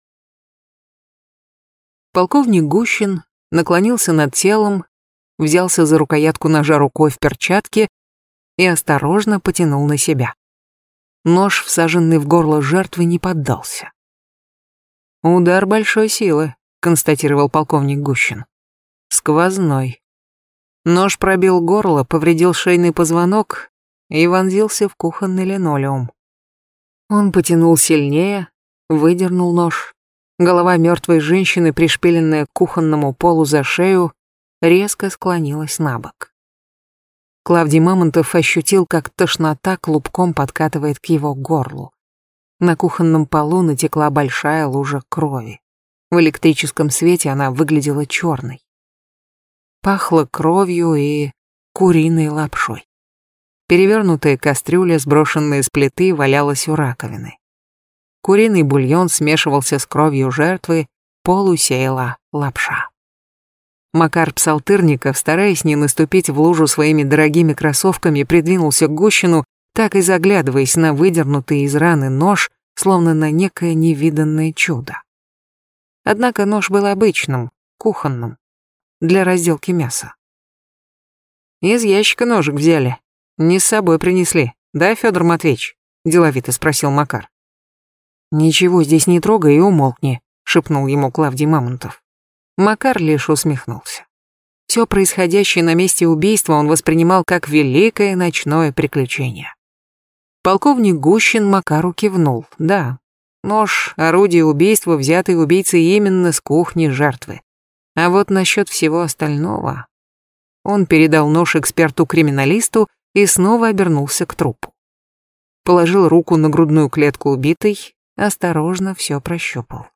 Аудиокнига Коридор затмений | Библиотека аудиокниг